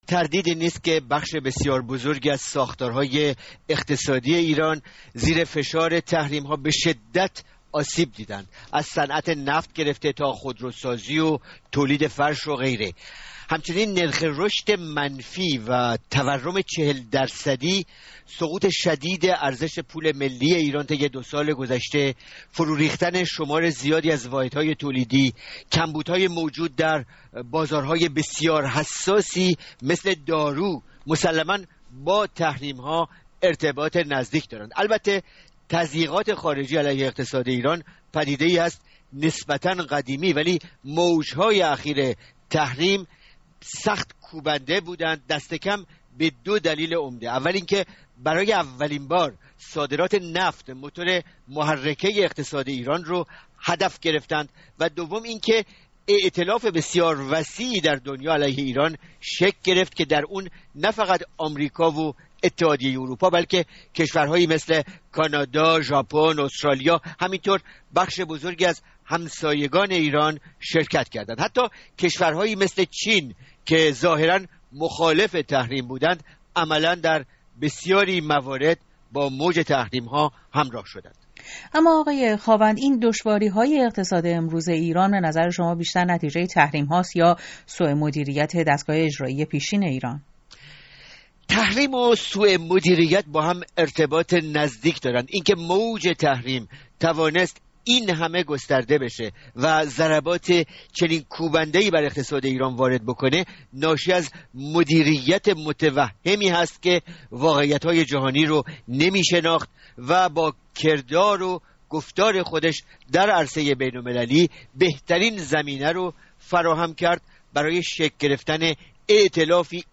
تحلیل